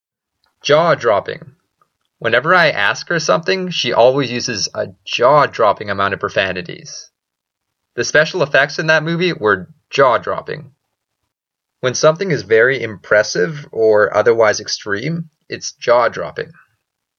英語ネイティブによる発音は下記のリンクをクリックしてください。
jawdropping.mp3